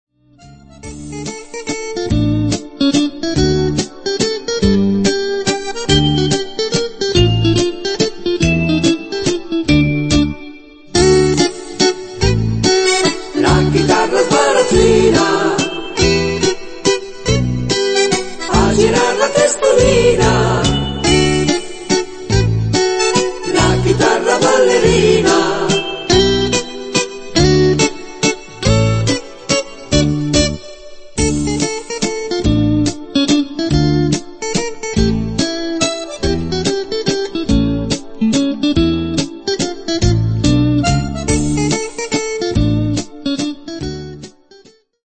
mazurca